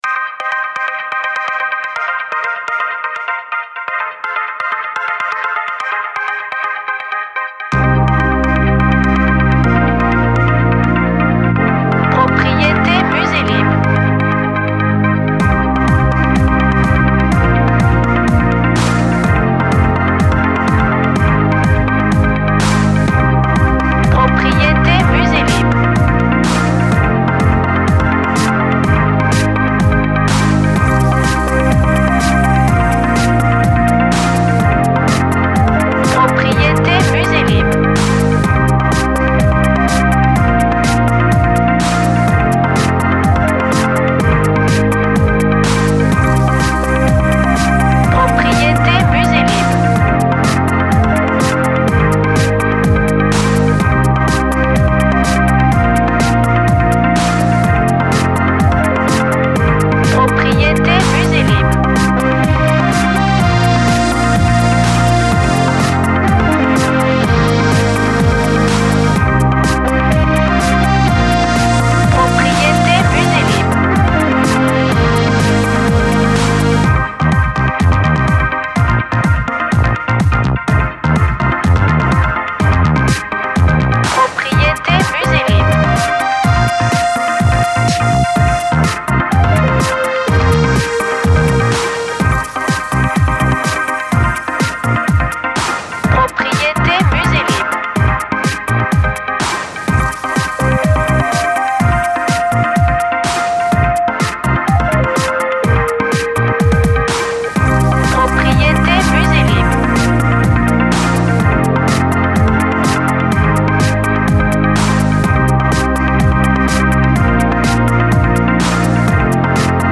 Chanson electro soft et dansante.